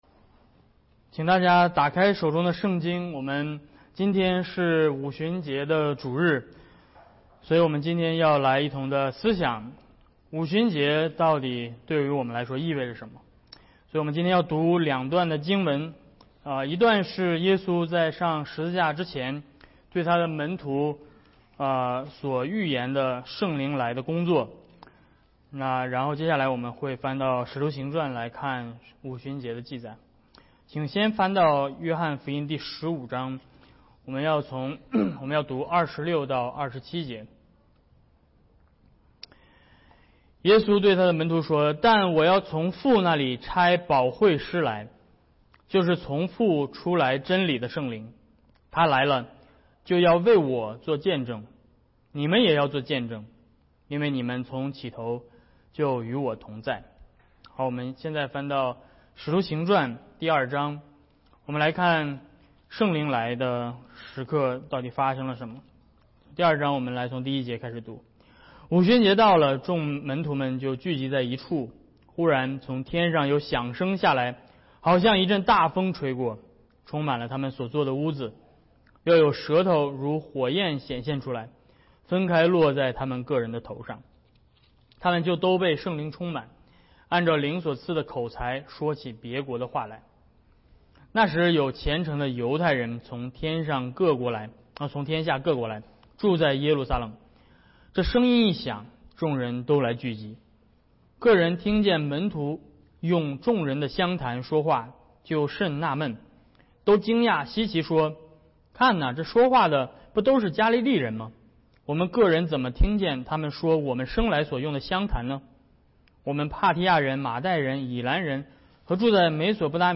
2022五旬节主日 | 他来是为我作见证（约翰福音15:26-27；使徒行传 2:1-42）
Acts 2:1-42 Service Type: 主日讲道 « 比利时信条